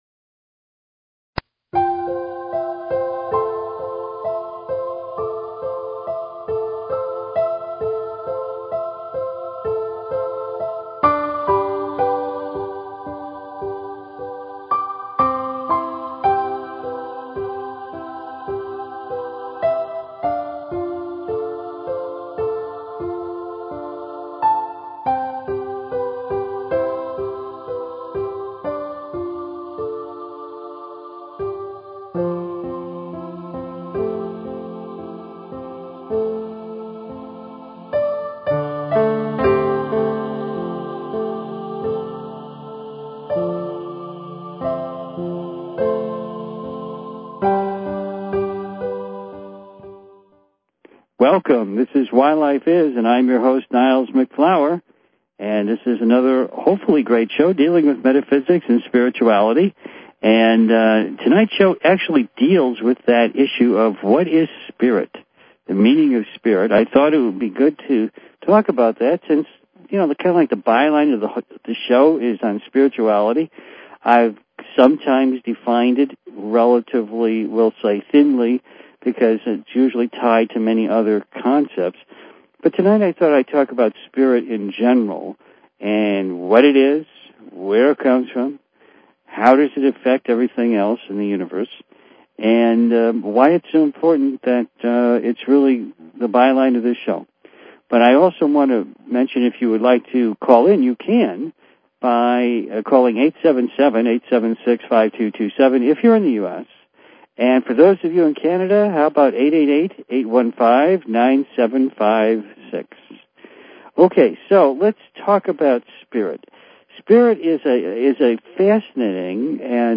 Subscribe Talk Show Why Life Is...